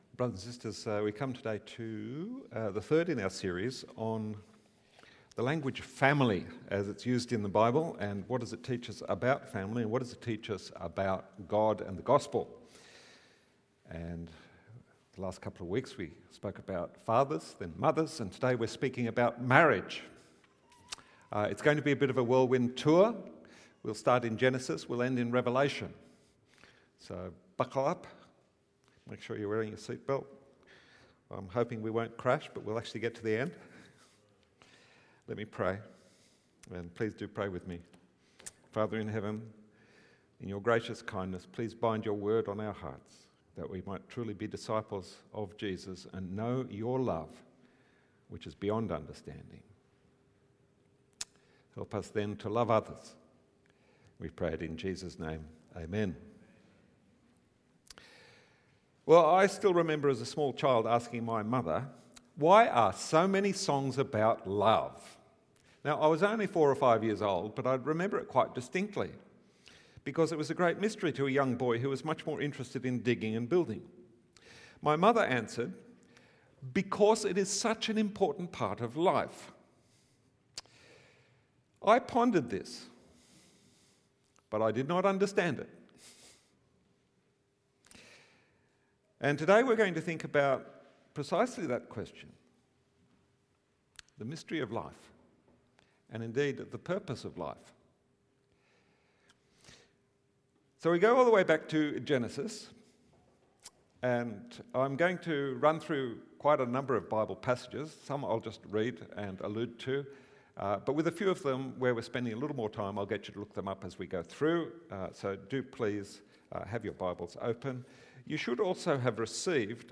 Sunday sermon
from St John’s Anglican Cathedral Parramatta.
Watch the full service on YouTube or listen to the sermon audio only.